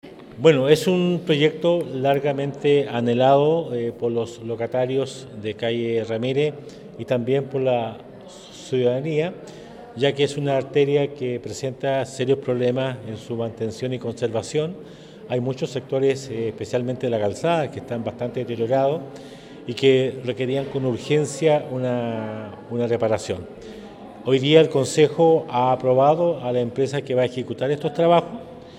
Este proyecto había sido solicitado por lo locatarios del sector centro, por los problemas que mantiene en especial en la zona de calzada, como explicó el Alcalde Emeterio Carrillo.